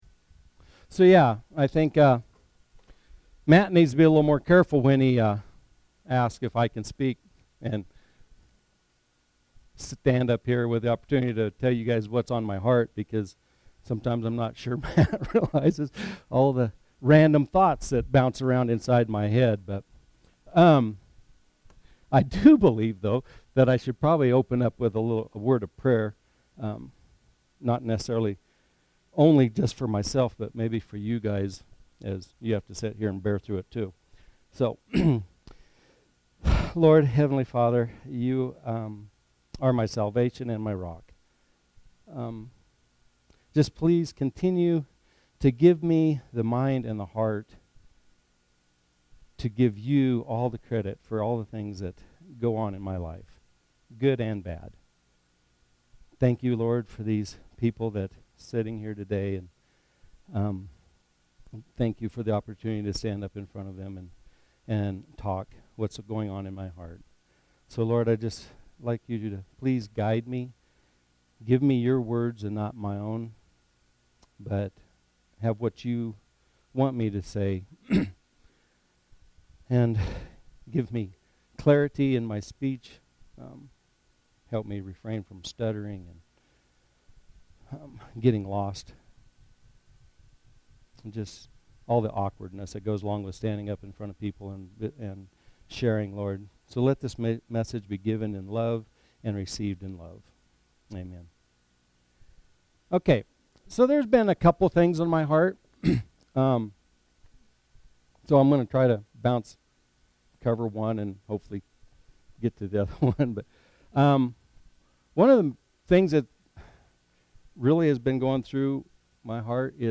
SERMON: Why do the godly suffer?